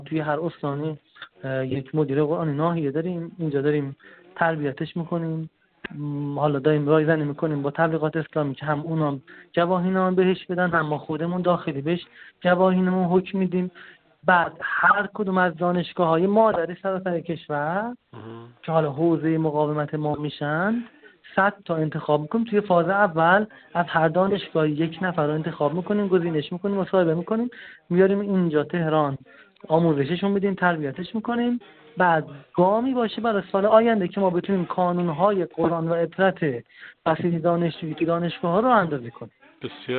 گفت‌و‌گو با خبرنگار ایکنا